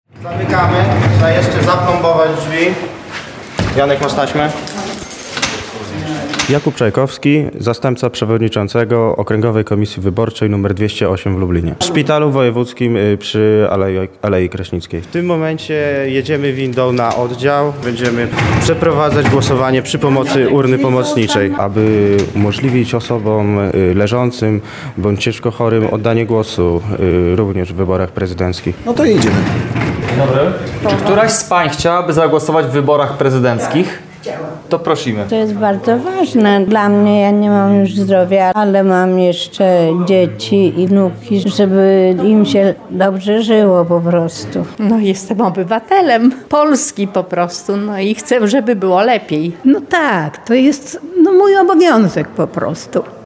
Tu komisje wyborcze udają się również do pacjentów, którzy nie mogą przyjść do lokalu wyborczego o własnych siłach. Tak było między innymi w Wojewódzkim Szpitalu Specjalistycznym im. Stefana Kardynała Wyszyńskiego w Lublinie.